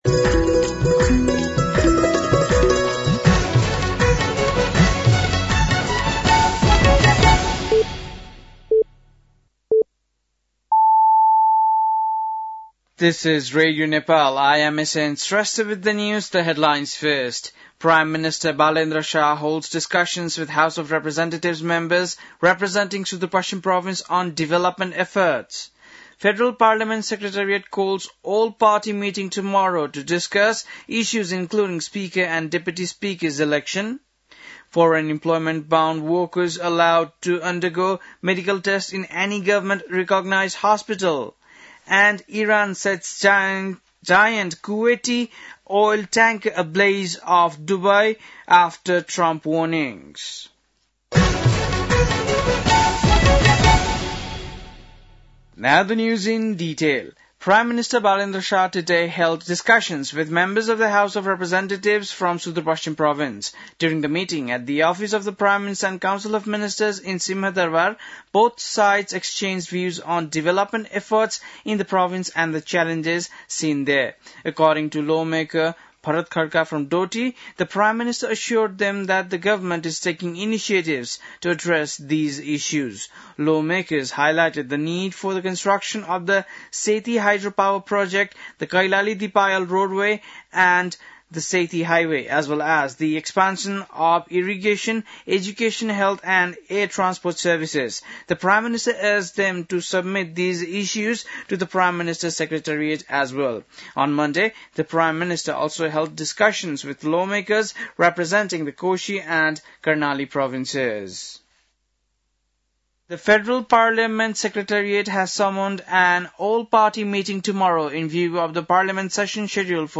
बेलुकी ८ बजेको अङ्ग्रेजी समाचार : १७ चैत , २०८२